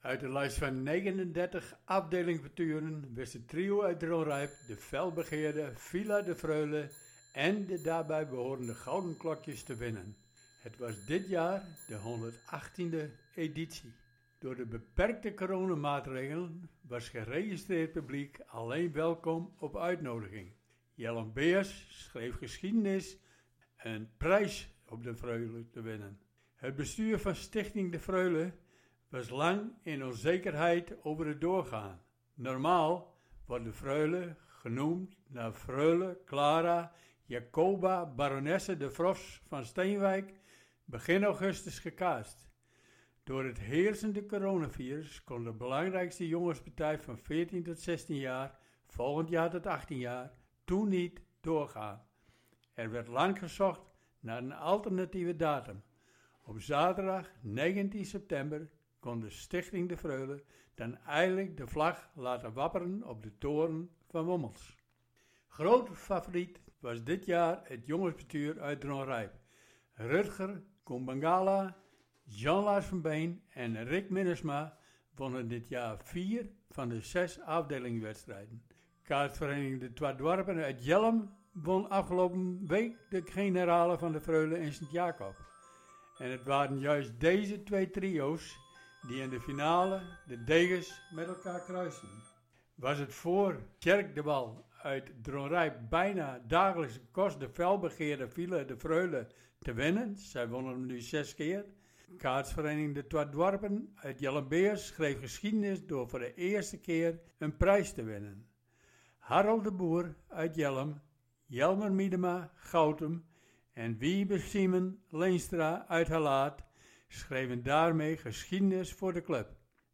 Verslag 118e Freule in 2020.